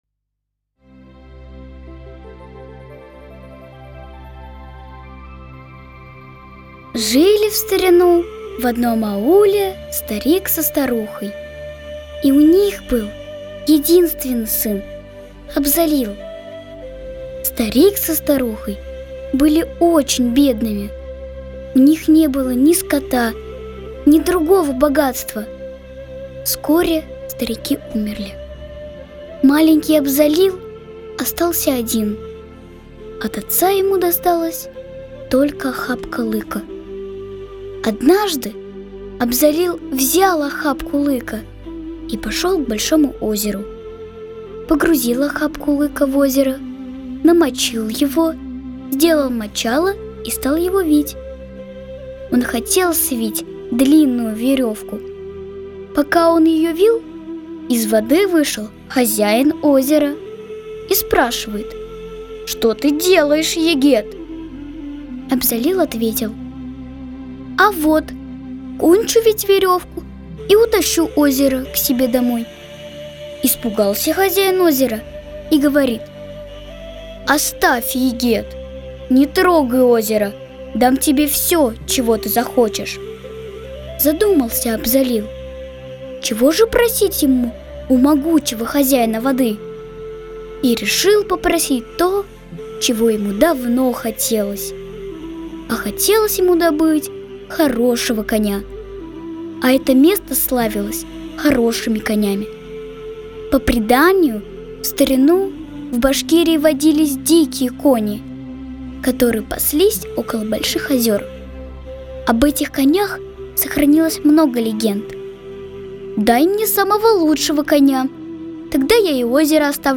Башкирская аудиосказка